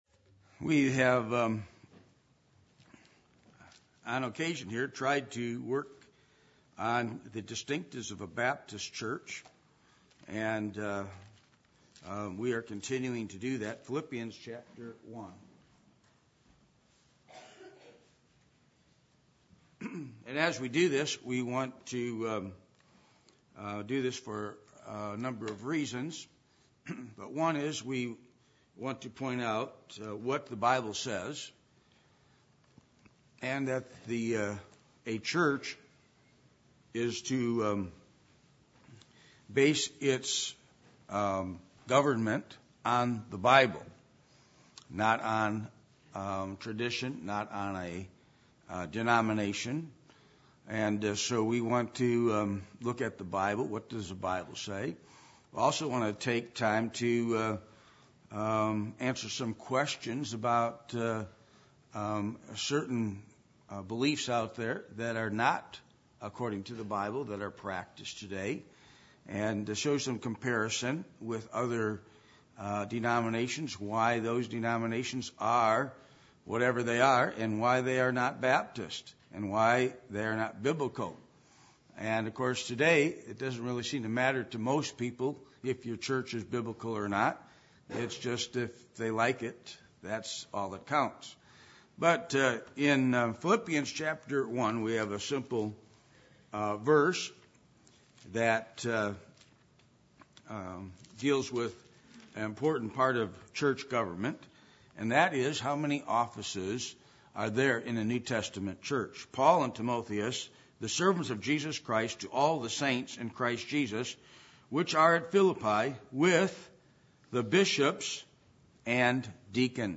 Passage: Philippians 1:1-2 Service Type: Midweek Meeting %todo_render% « What Are The Marks Of A Leader?